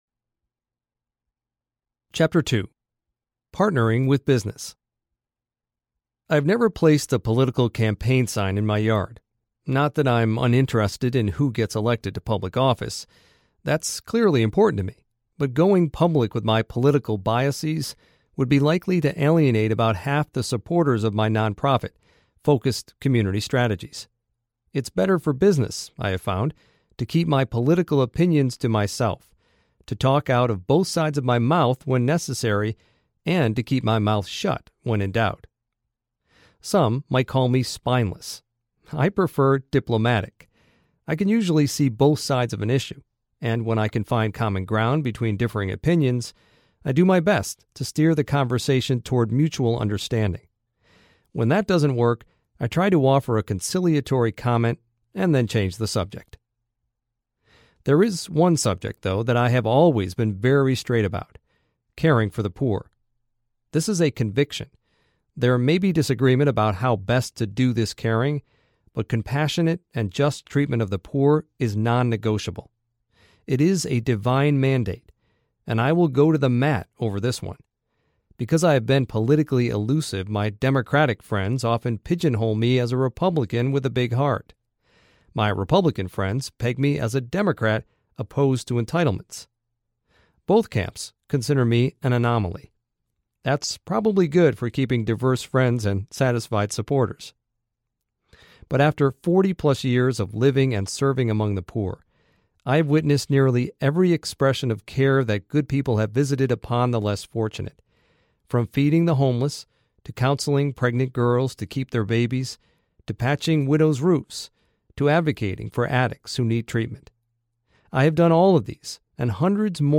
Charity Detox Audiobook
Narrator
5.1 Hrs. – Unabridged